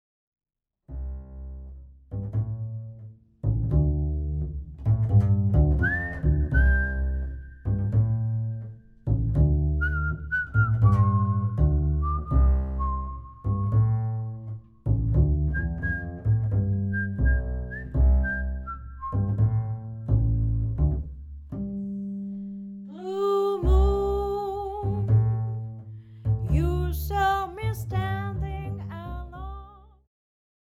ジャンル Fusion
Jazz
アコースティック
ボーカルフューチュア
うたとベースが織り成すハーモニー。シンプルな編成だから聞こえてくる言葉と音。
静かに熱い、スリリングだけどハートウォーミング。
コンプレッサーを一切使わず生音を重視した録音です。うたの息遣いやベースの残響の奥深さを感じていただける一枚です。